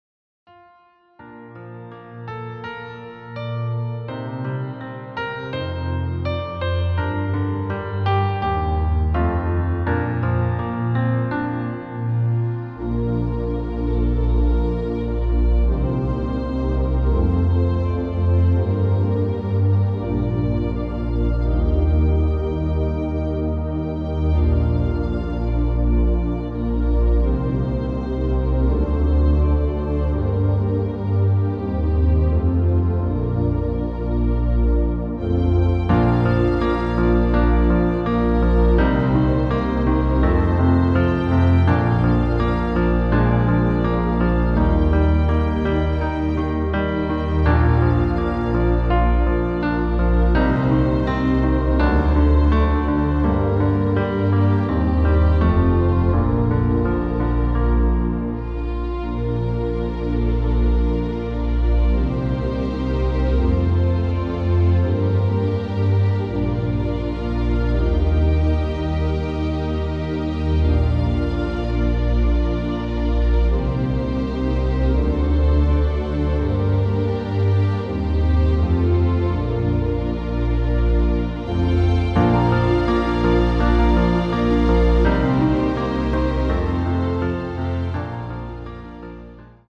• Tonart: Ab Dur, Bb Dur (Originaltonart )
• Art: Klavierversion mit Streichern
• Das Instrumental beinhaltet NICHT die Leadstimme
• Kurzes Vorspiel
• Kurzer Break
• Tonerhöhung